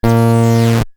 Hum05.wav